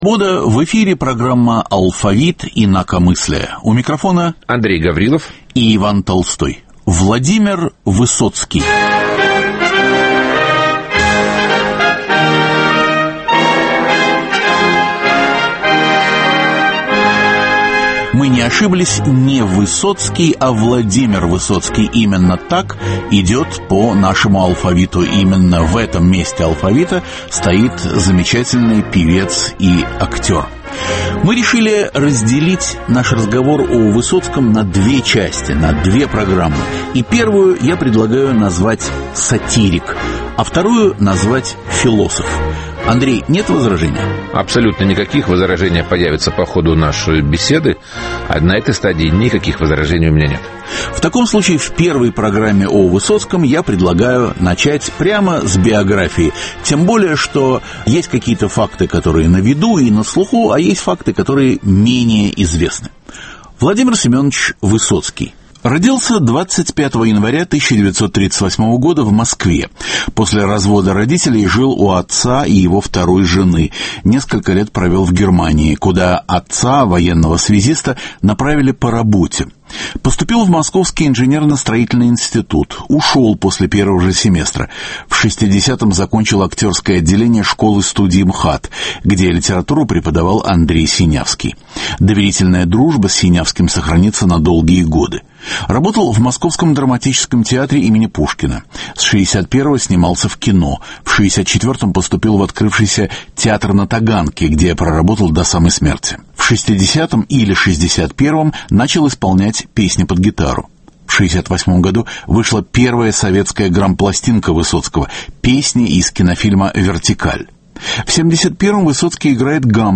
В программе представлено творчество барда 1960-х годов. Звучат голоса Виктора Некрасова и Марии Розановой.